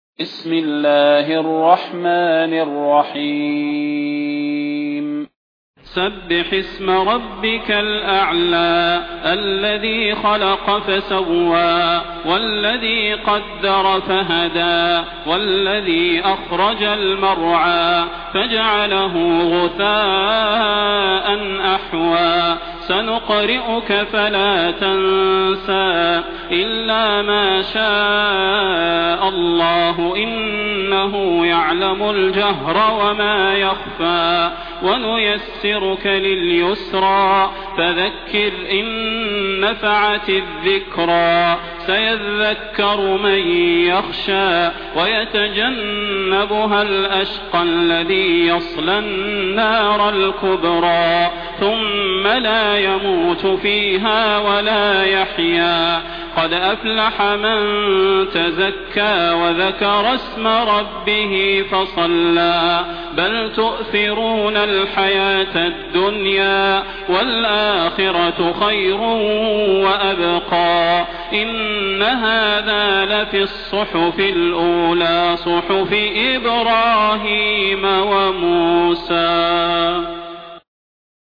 المكان: المسجد النبوي الشيخ: فضيلة الشيخ د. صلاح بن محمد البدير فضيلة الشيخ د. صلاح بن محمد البدير الأعلى